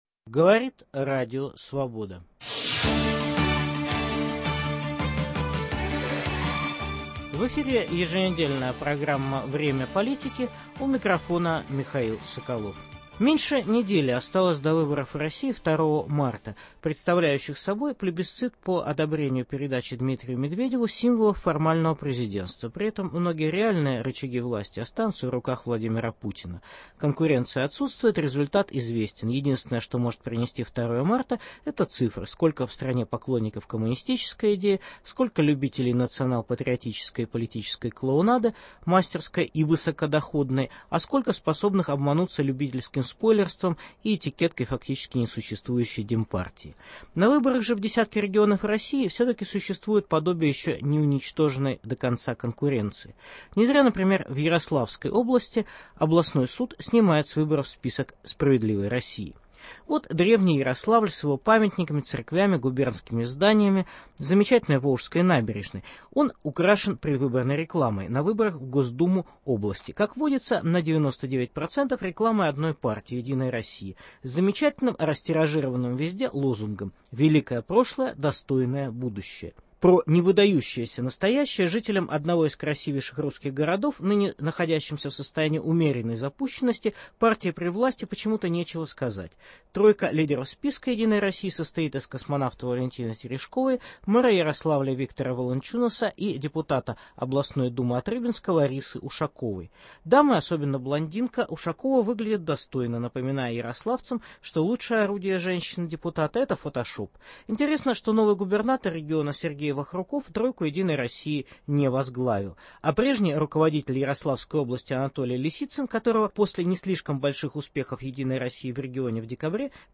Специальный репортаж из Ярославля: "Справедливая Россия" снята с выборов Госдумы Ярославской области. Выборы Законодательного Собрания Ростовской области. Муниципальные выборы в Москве и Петербурге.